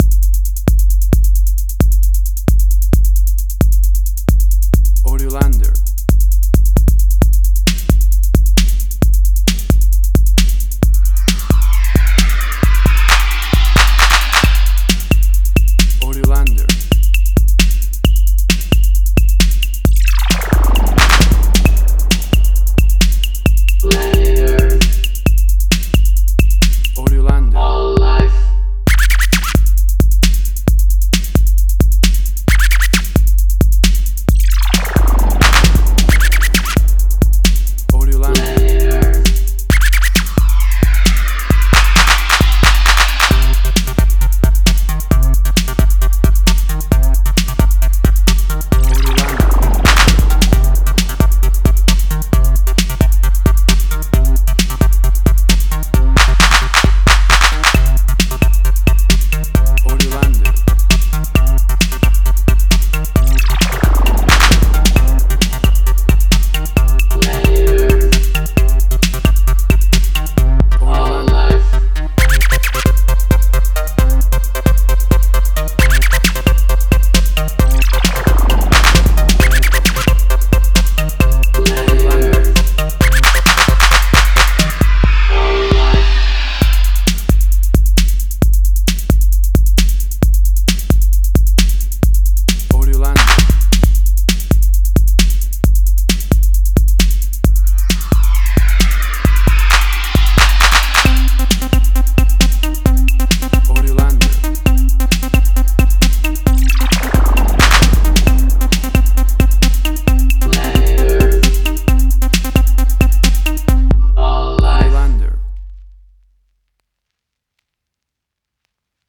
emotional music
Tempo (BPM): 133